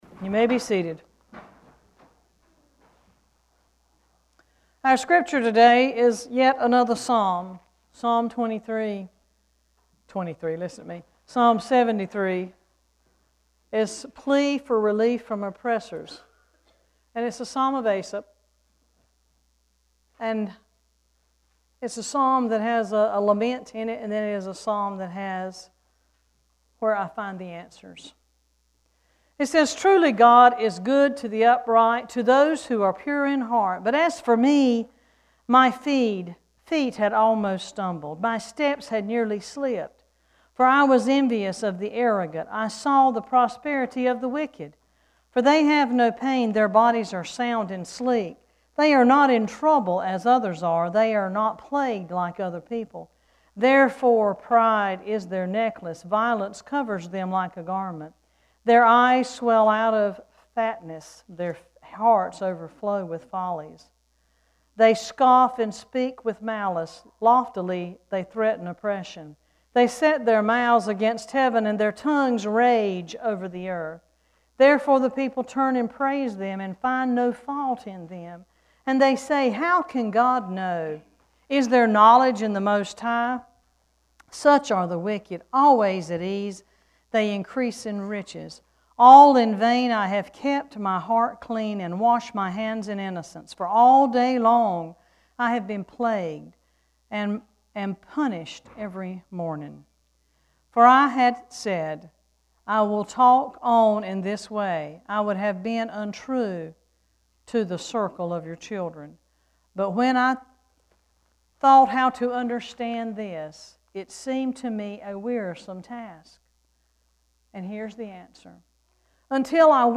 Worship Service 1-11-15: The Place of Renewal
1-11-15-scripture.mp3